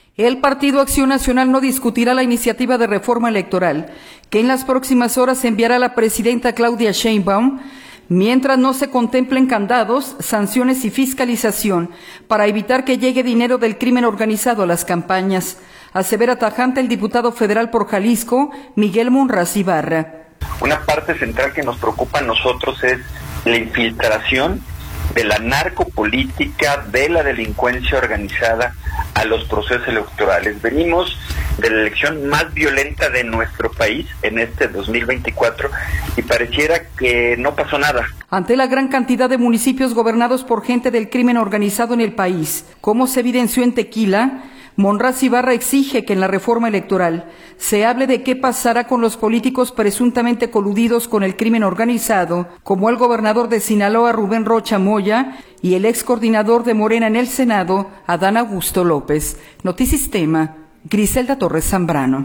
El Partido Acción Nacional no discutirá la iniciativa de Reforma Electoral que en las próximas horas enviará la presidenta Claudia Sheinbaum, mientras no se contemplen candados, sanciones y fiscalización para evitar que llegue dinero del crimen organizado a las campañas, asevera tajante el diputado federal por Jalisco, Miguel Monraz Ibarra.